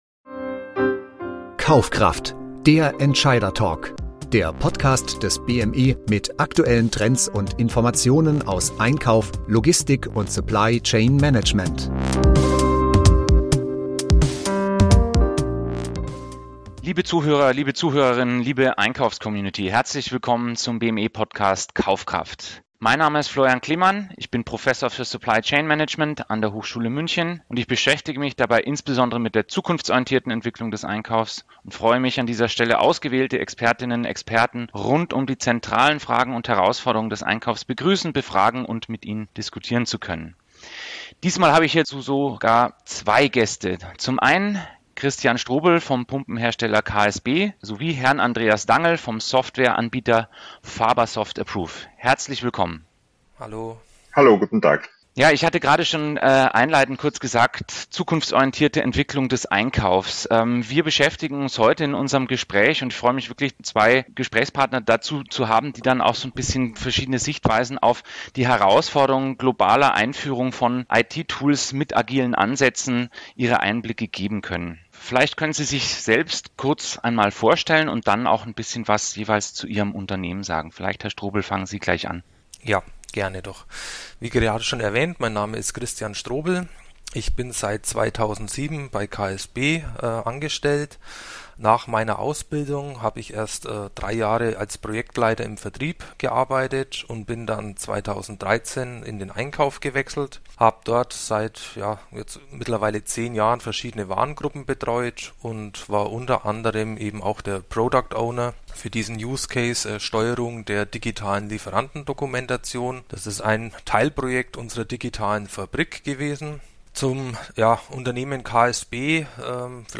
Dieses Interview verspricht nicht nur Einblicke, sondern auch wertvolle Erkenntnisse für Unternehmen, die ihre eigene digitale Transformation vorantreiben wollen.